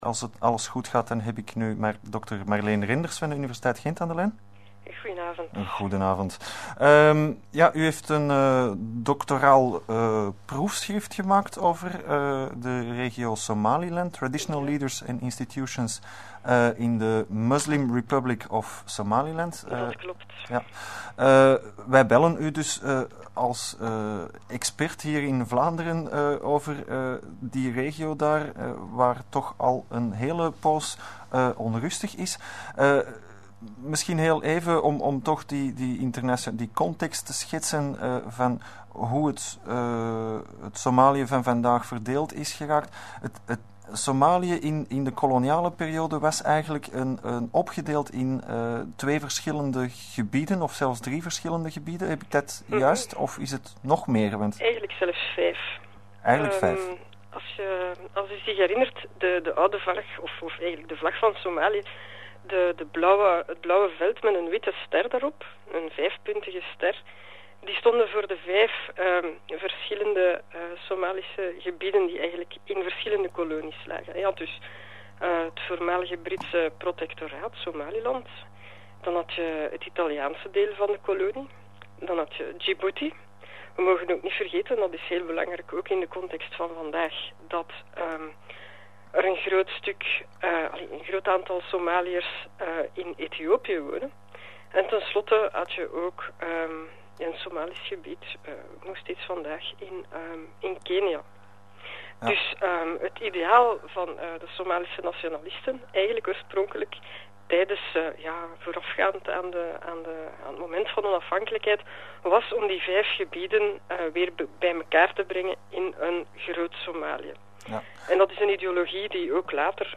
Uitzending Vrije Radio – 18 december 2007